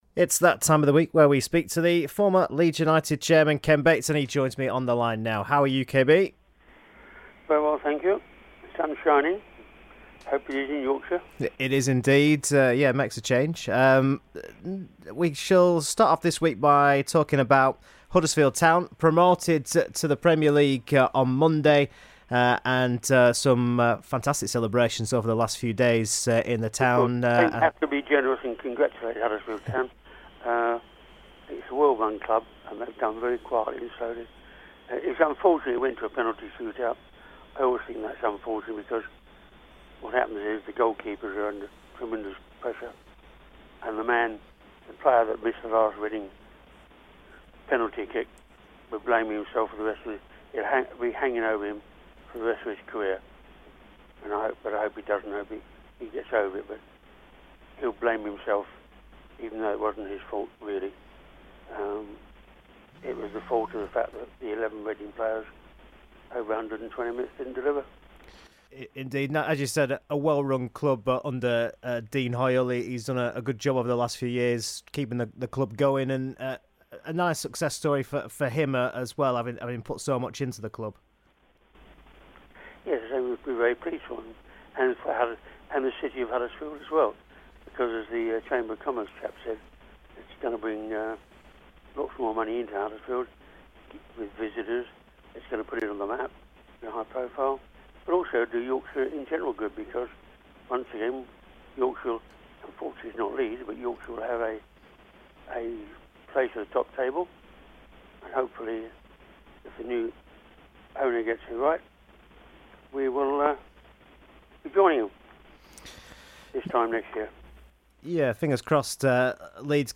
Ken Bates Interview 1 June